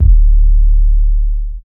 Club8-0-8_YC.wav